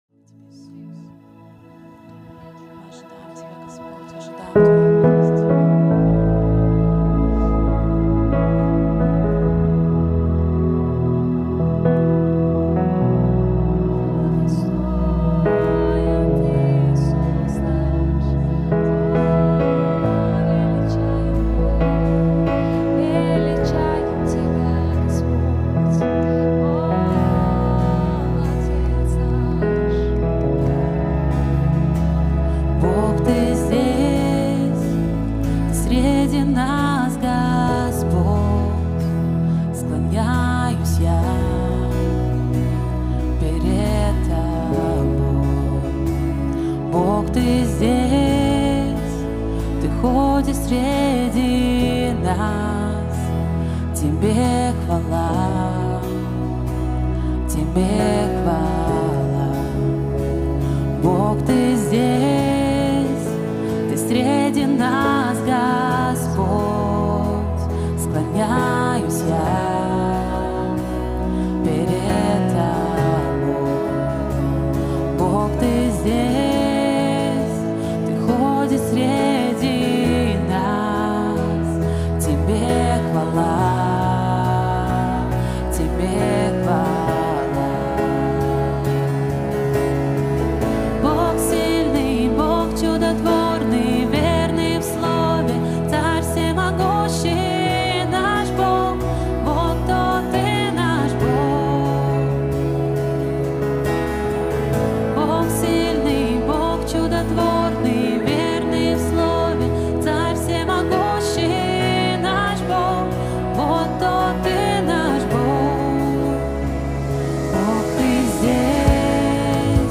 1126 просмотров 713 прослушиваний 54 скачивания BPM: 130